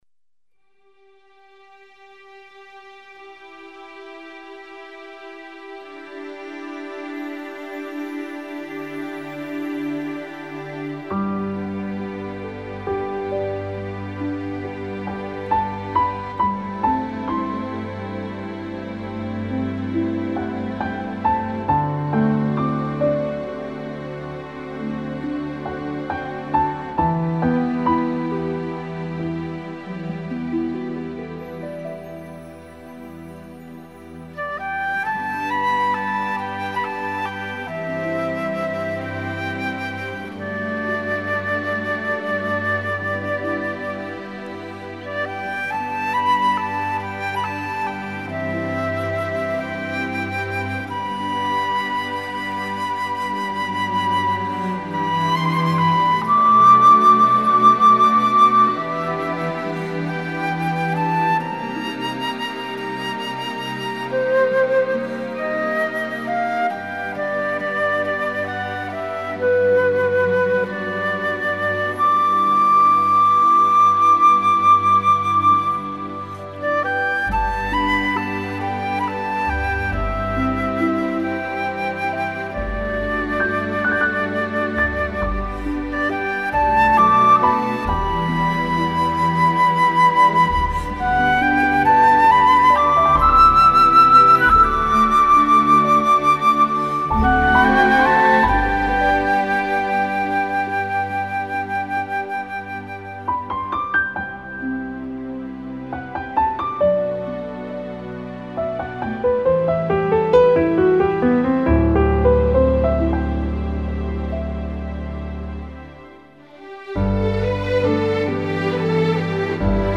音樂類型：純音樂